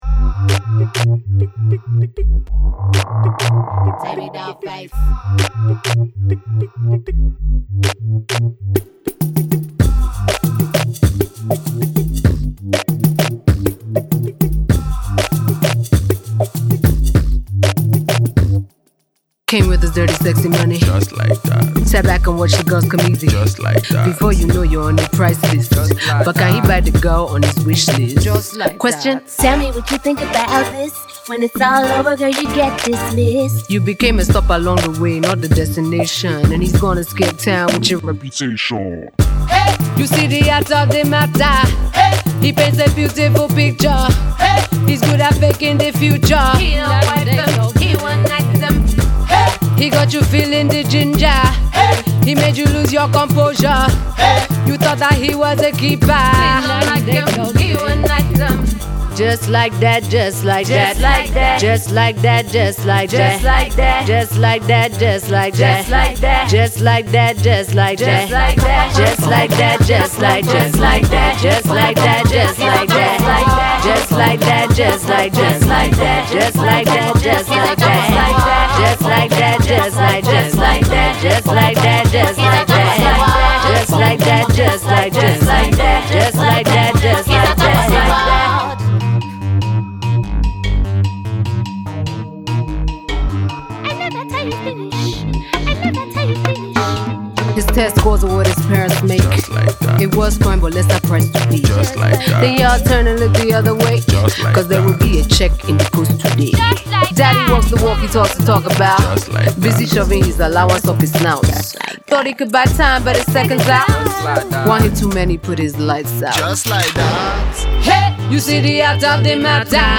a mix of hip-hop and afrobeat
rapping, singing and hitting high notes in the song